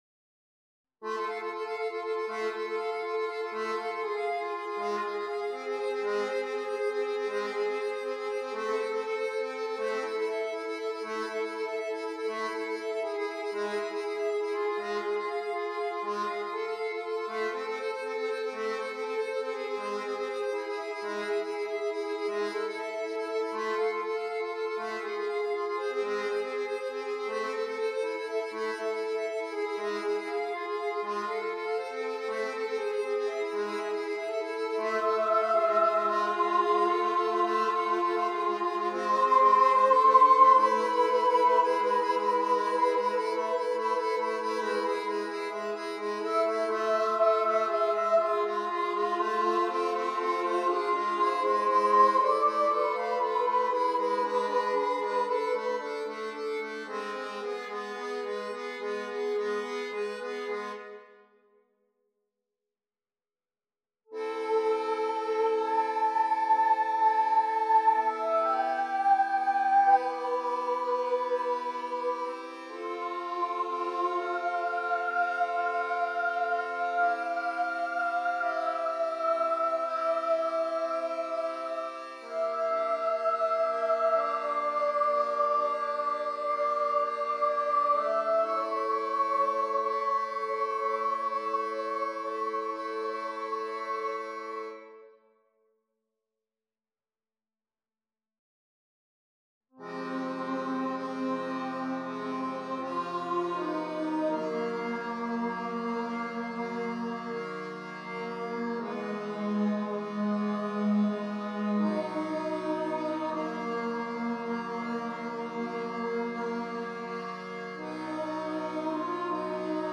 Genre chamber music
Instrumentation mezzo soprano and accordion
Musical styles and elements modal, melodic